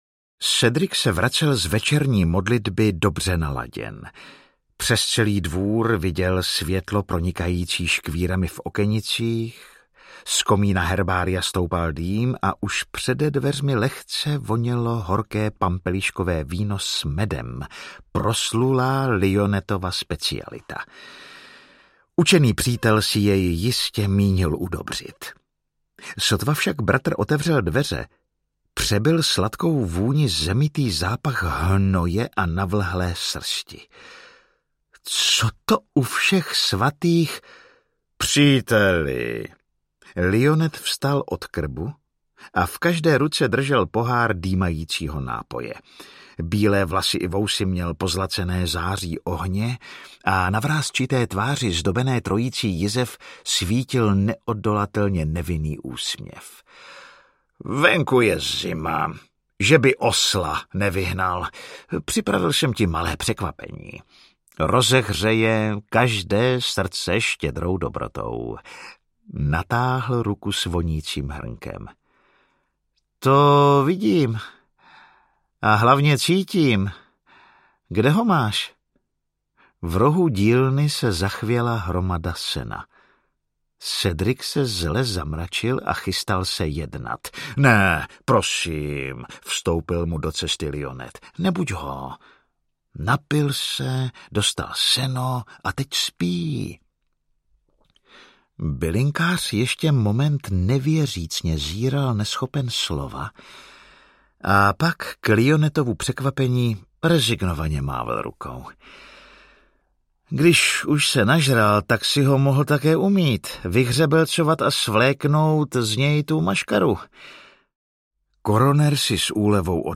Lionet & jiné podivné případy audiokniha
Ukázka z knihy
Vyrobilo studio Soundguru.